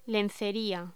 Locución: Lencería
voz